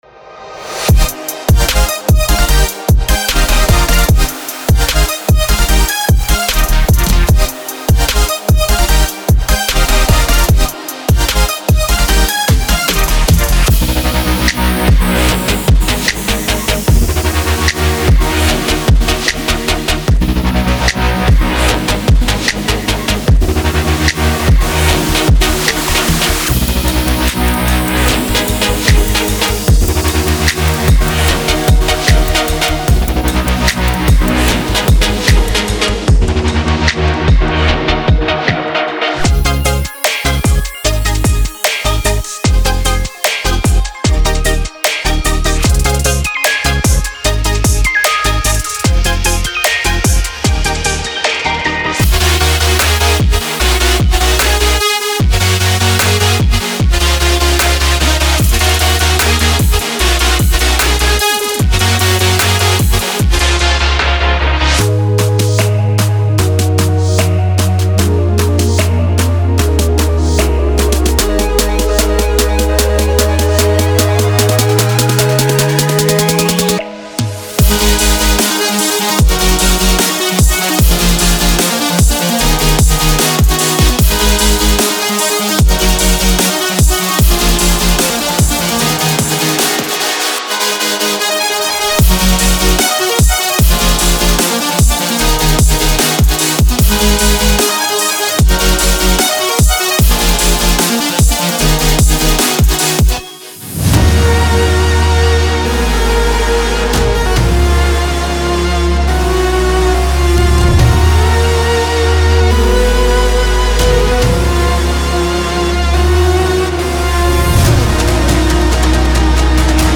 future bass sounds for xfer serum synthesizer
The drums used are for demonstration purposes only.
MP3 DEMO